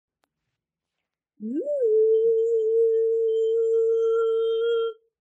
Sonido psicodélico de un Theremín
Breve grabación sonora del sonido electrónico psicodélico de un theremín
instrumento musical
psicodélico
electrónico